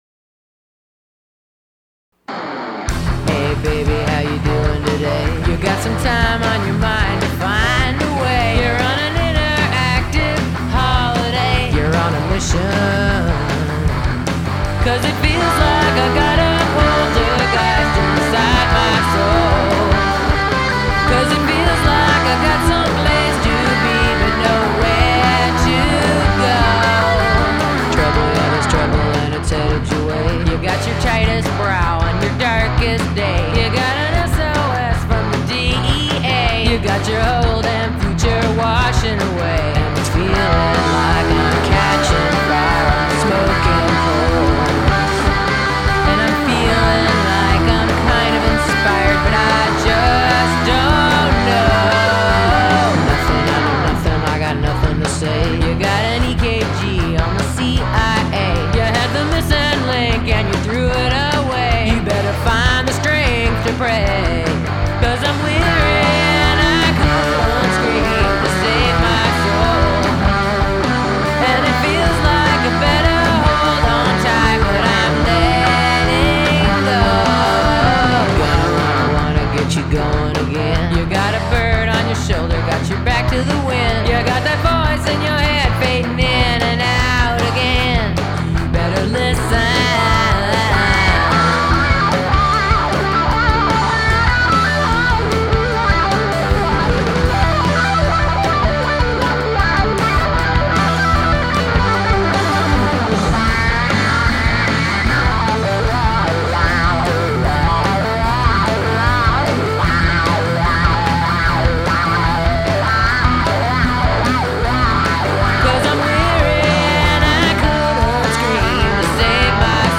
Lead Guitar
Guitar
Bass
Drums